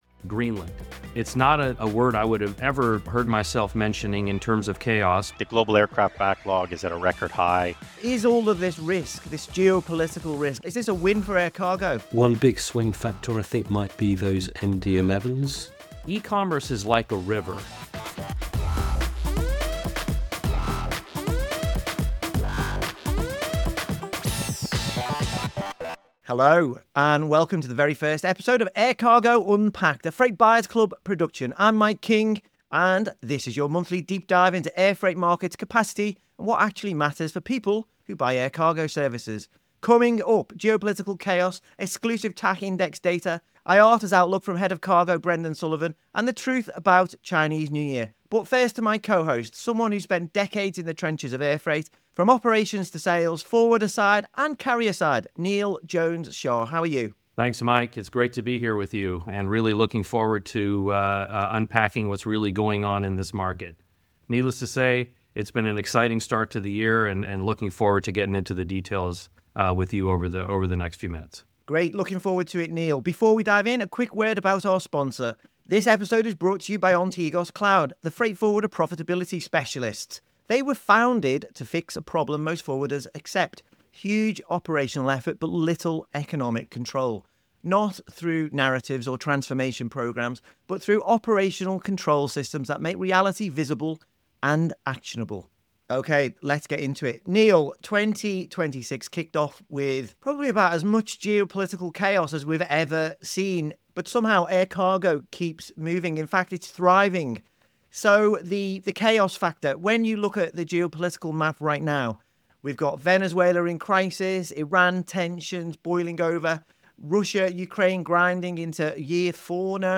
Co-hosted